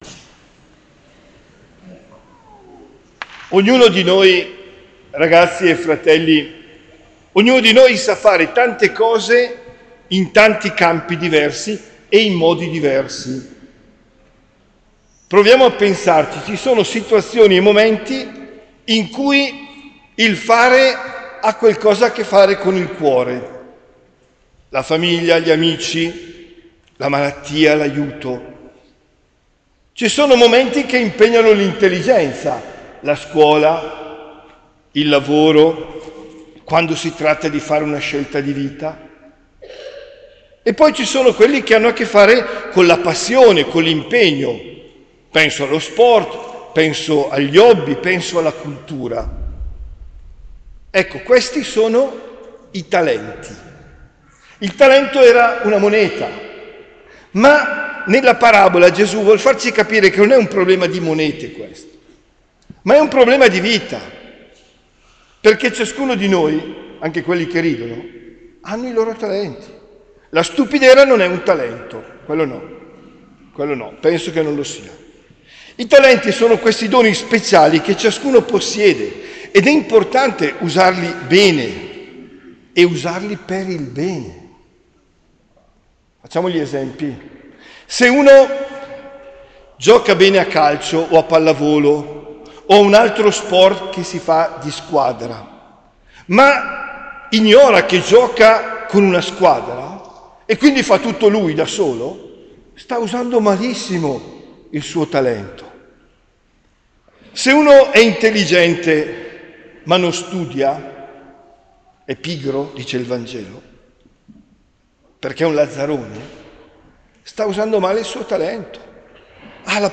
OMELIA DEL 19 NOVEMBRE 2023